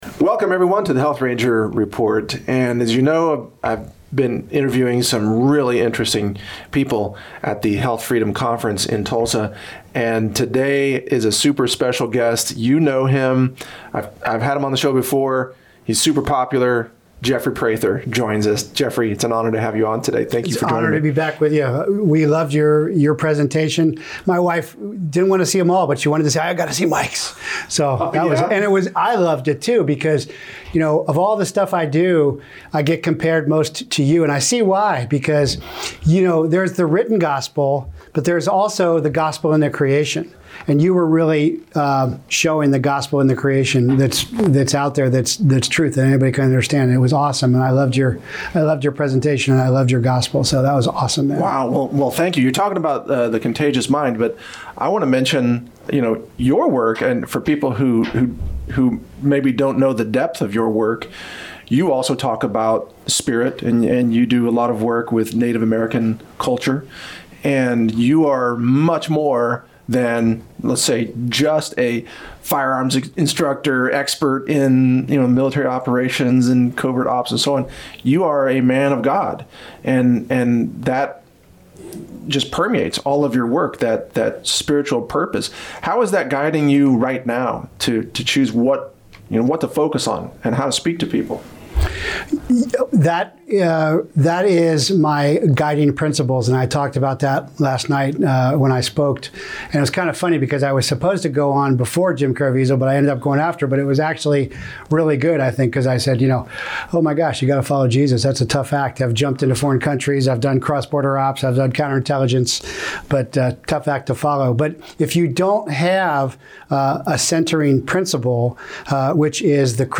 Health Ranger Report Interview